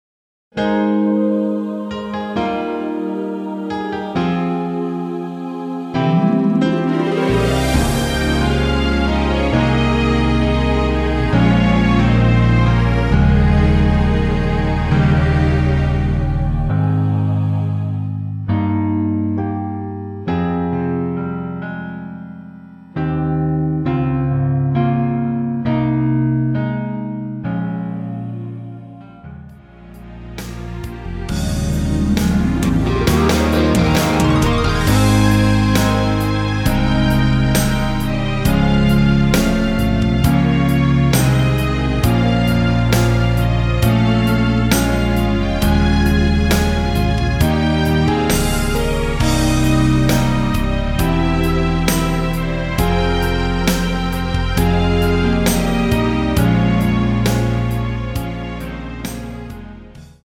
원곡의 보컬 목소리를 MR에 약하게 넣어서 제작한 MR이며
노래 부르 시는 분의 목소리가 크게 들리며 원곡의 목소리는 코러스 처럼 약하게 들리게 됩니다.